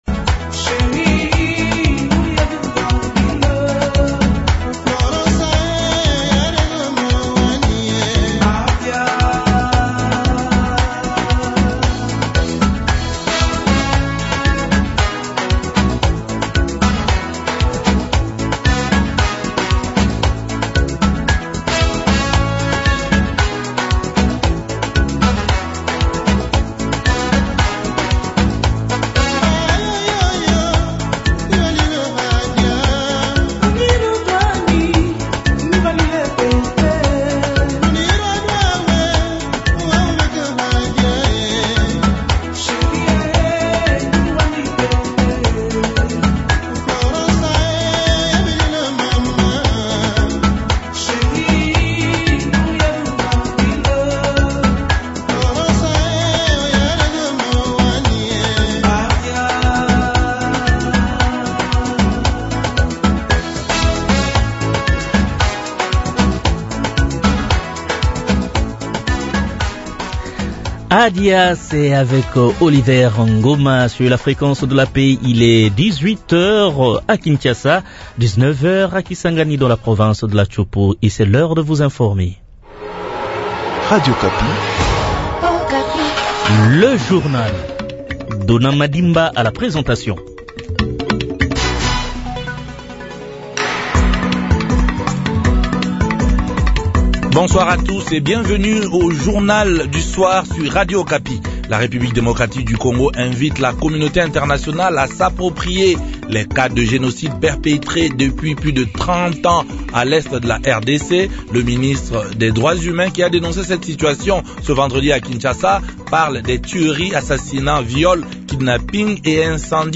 journal francais
Nous recevons dans ce journal le député national Jethro Muyombi, cadre de l’Union sacrée de la nation uSN. Il s’exprime notamment sur la question des pétitions visant certains membres du bureau de l’Assemblée nationale de la Rdc.